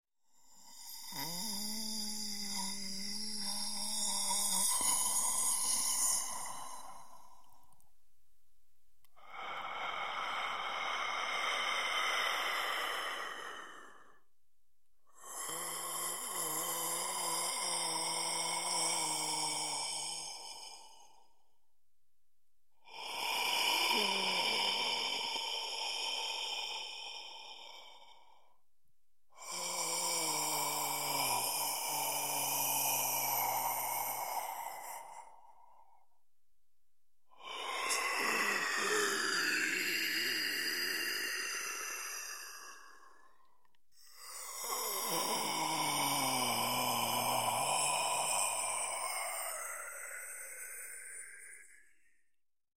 Звуки хрипа
На этой странице собраны звуки хрипов разного характера: сухие и влажные, свистящие и жужжащие.
старик осип и хрипит, дышит с трудом